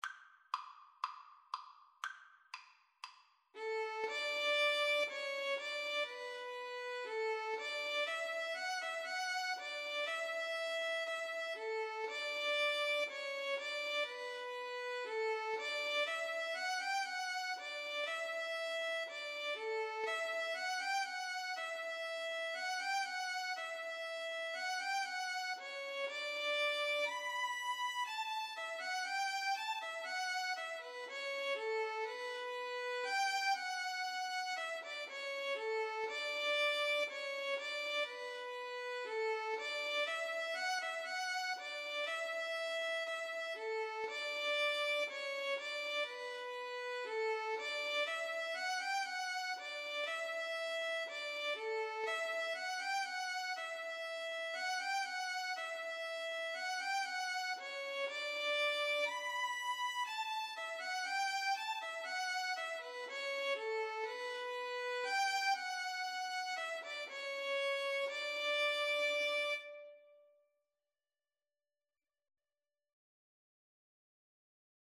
Allegro Moderato = 120 (View more music marked Allegro)
4/4 (View more 4/4 Music)
Violin-Cello Duet  (View more Easy Violin-Cello Duet Music)
Classical (View more Classical Violin-Cello Duet Music)